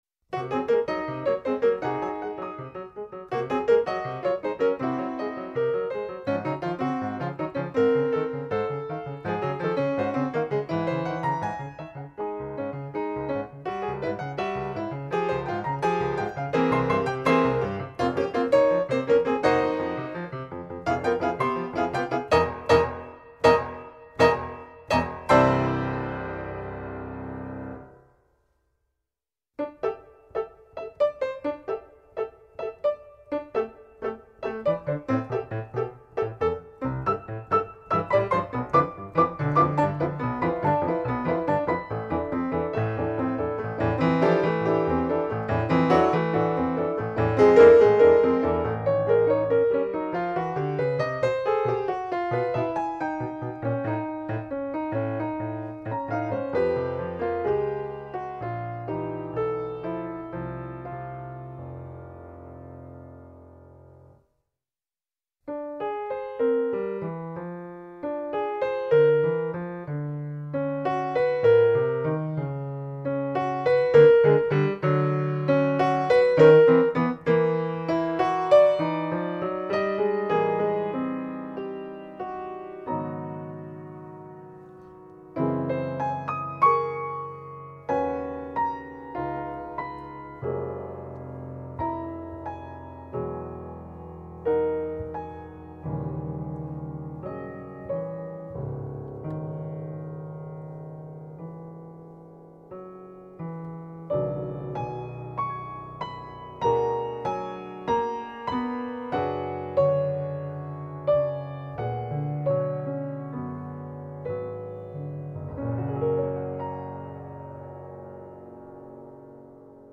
mezzo di esecuzione: pianoforte a 4 mani